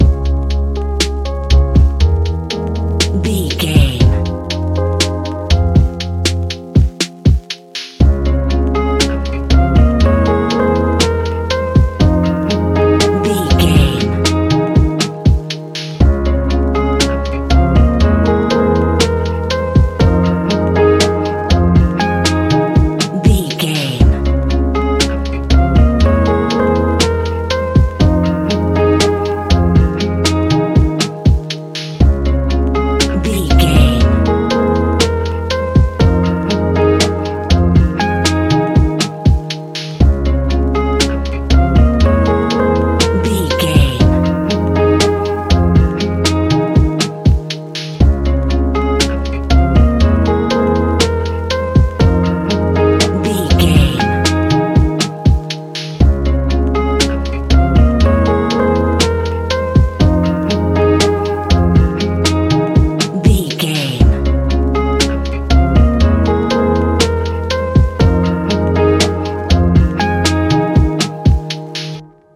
Ionian/Major
F♯
chilled
laid back
Lounge
sparse
new age
chilled electronica
ambient
atmospheric
morphing
instrumentals